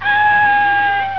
ChunLi-dying.wav